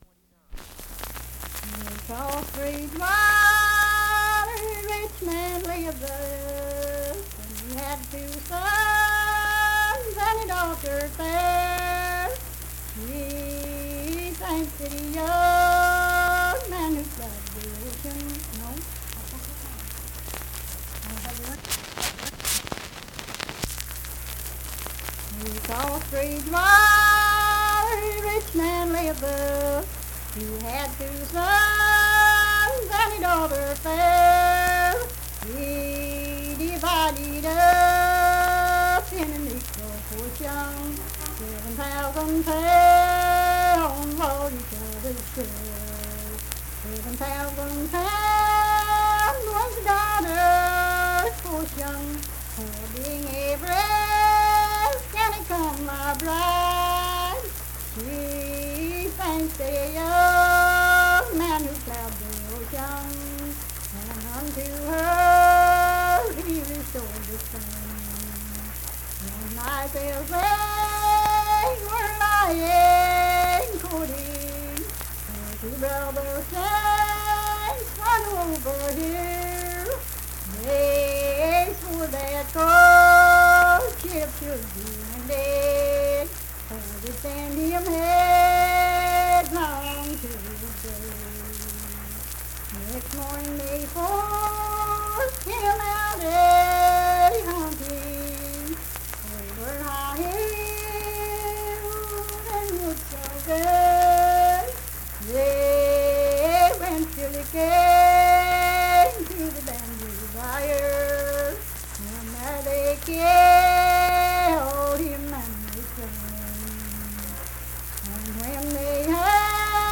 Unaccompanied vocal music performance
Verse-refrain 6(4).
Voice (sung)
Kirk (W. Va.), Mingo County (W. Va.)